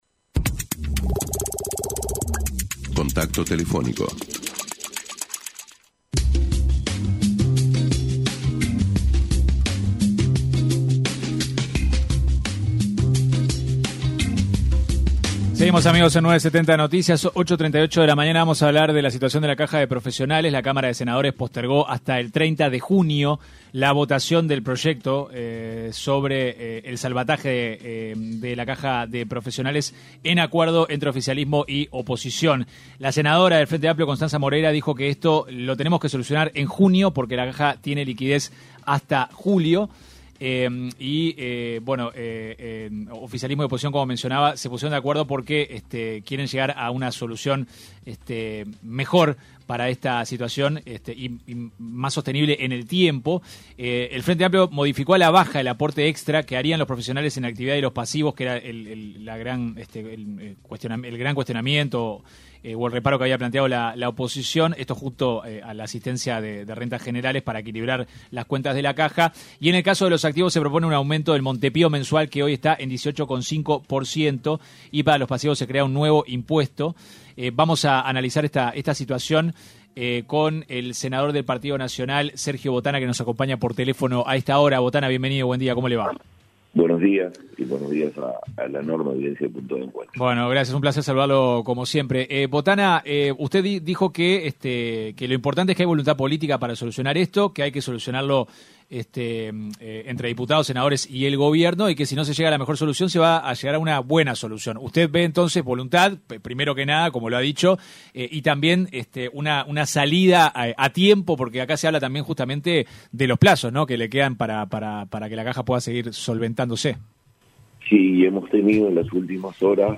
El senador del Partido Nacional, Sergio Botana, en diálogo con 970 Noticias, mostró su preocupación tras la decisión del directorio de la Caja de Profesionales, de fraccionar los pagos a pasivos correspondientes para julio en dos partes.